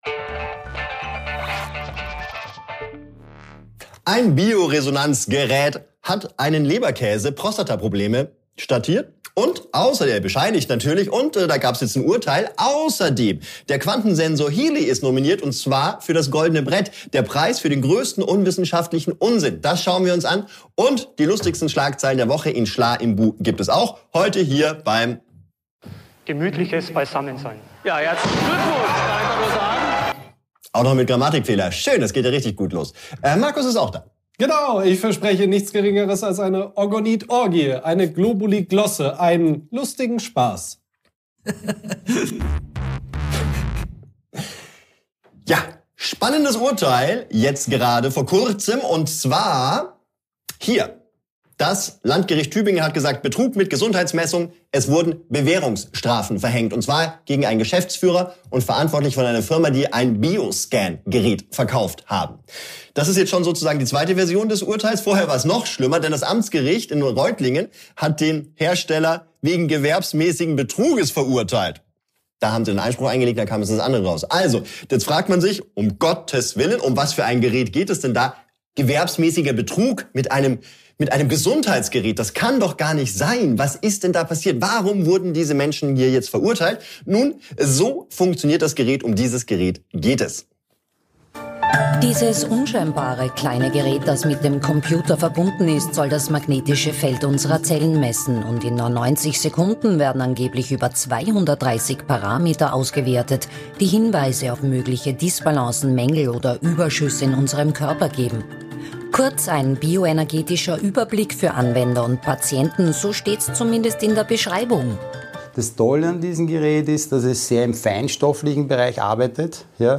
Der dreisteste Esoterik-Betrug des Jahres ~ WALULIS Live Podcast